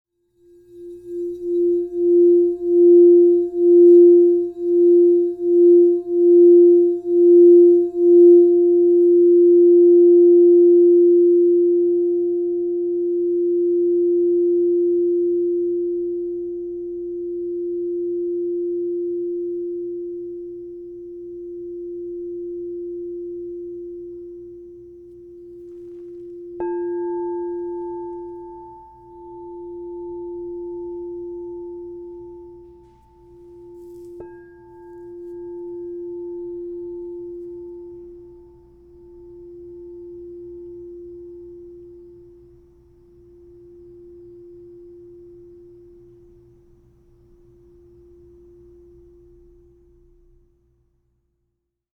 Rose Quartz, Palladium 7" F -5 Crystal Tones singing bowl
Discover the power of 7″ Crystal Tones® alchemy singing bowl made with Rose Quartz, Palladium in the key of F -5.
-5 (True Tone)
432Hz (-), 440Hz (TrueTone)